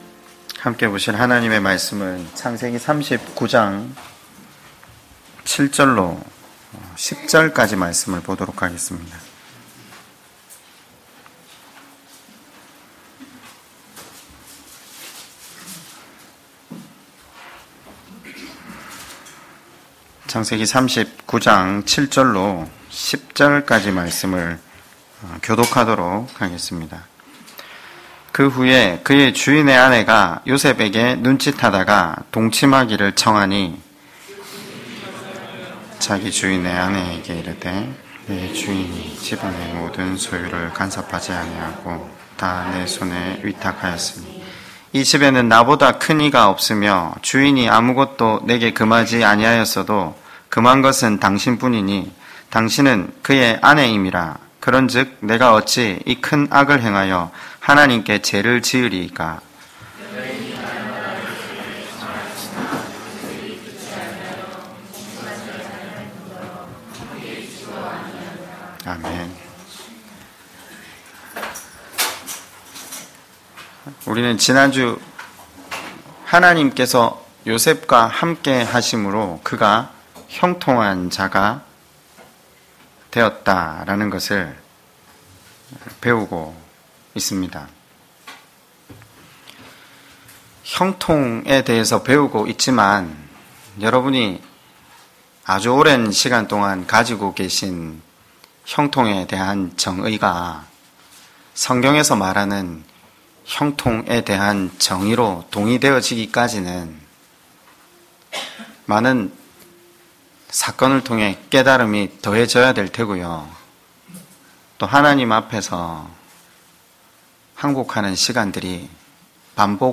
2020년2월16일 주일설교 (만사형통3 _ 창 39장7-16절).mp3